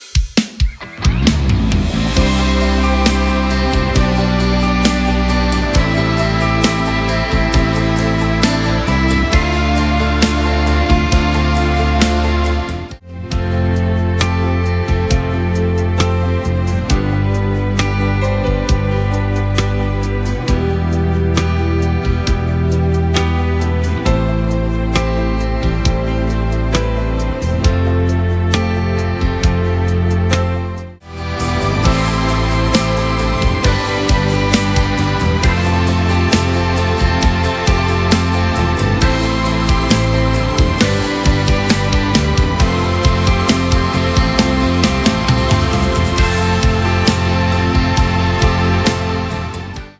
Así suena el Multitrack completo 🔽
• Compás: 4/4
• Tono: C
• BPM: 67 – 52
• Drums
• Bass
• Loop/Percusión
• Guitarra eléctrica 1-2
• Guitarra acústica
• Hammond
• Piano
• Pad/Strings
• Keys